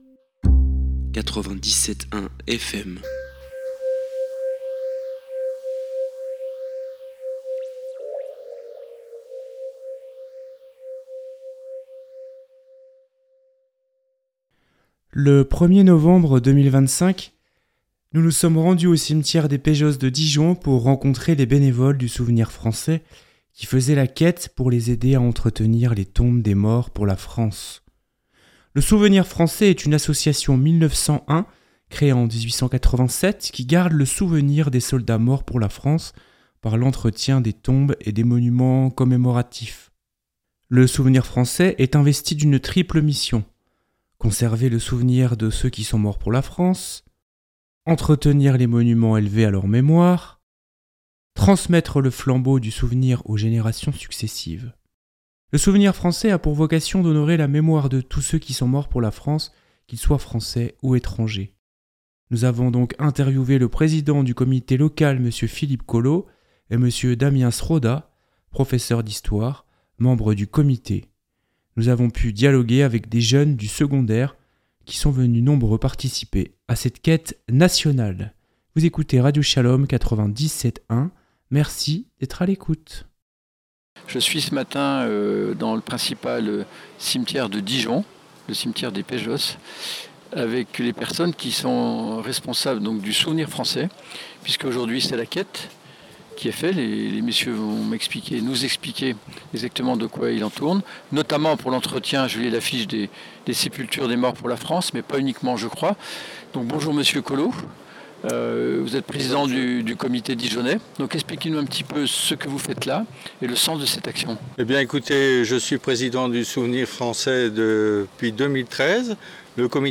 05 novembre 2025 Écouter le podcast Télécharger le podcast Le 1 ier novembre 2025, nous nous sommes rendus au Cimetière des Péjoces de Dijon pour rencontrer les bénévoles du Souvenir Français qui faisaient la quête pour les aider à entretenir les tombes des morts pour la France.
Nous avons pu dialoguer avec des jeunes du secondaire qui sont venus nombreux participer à cette quête nationale.